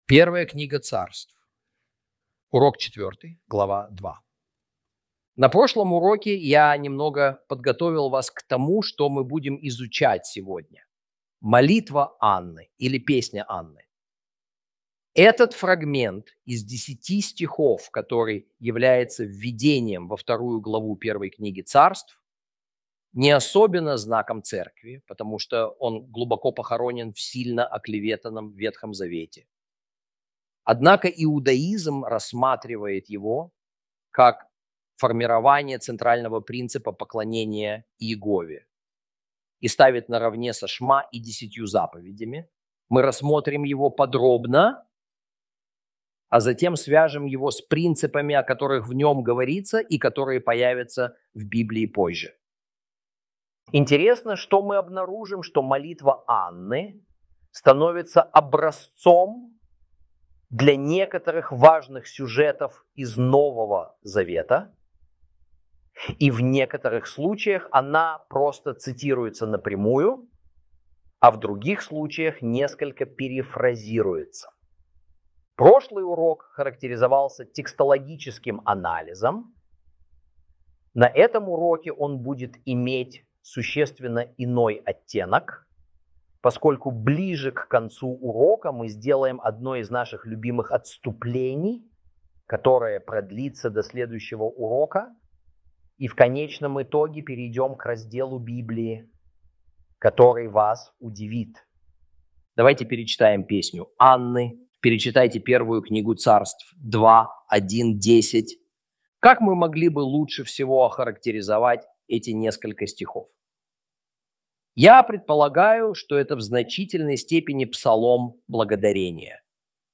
ru-audio-1-samuel-lesson-4-ch2.mp3